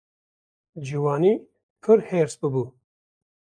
/heːɾs/